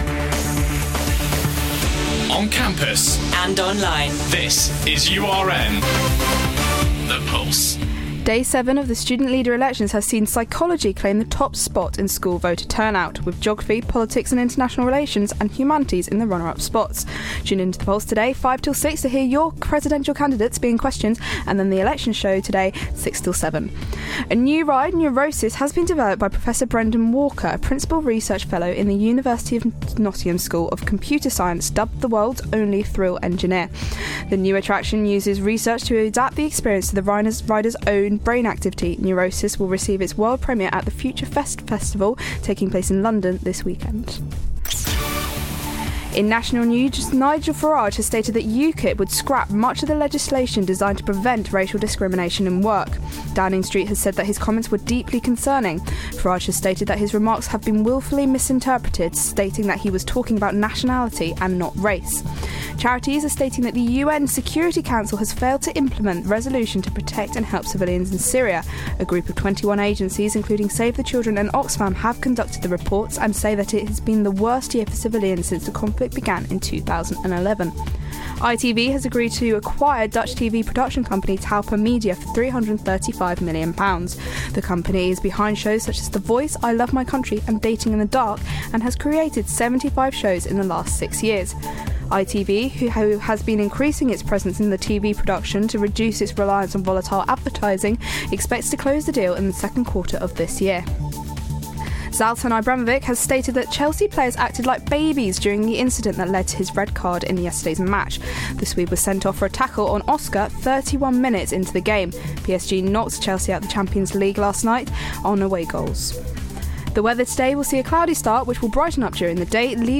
All of your latest headlines on URN